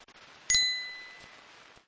Bell sound 4
(This is a lofi preview version. The downloadable version will be in full quality)
JM_Tesla_Lock-Sound_Bell-4_Watermark.mp3